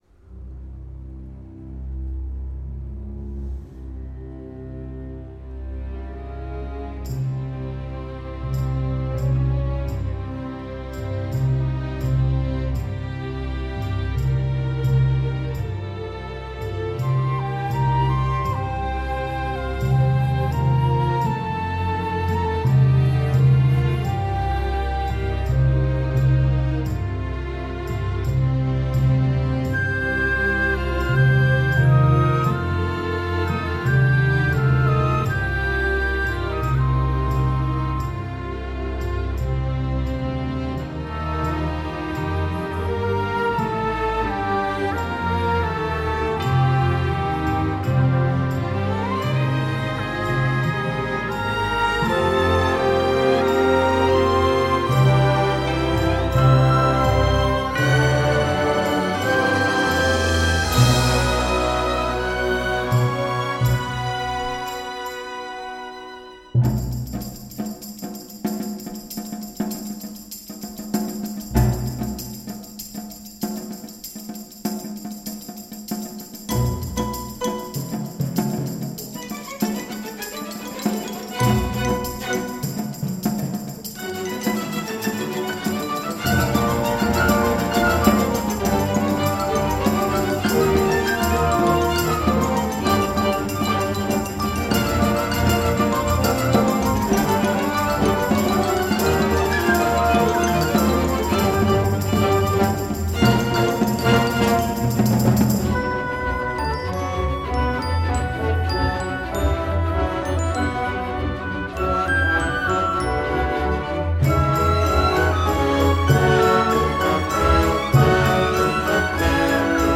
Симфоническая картина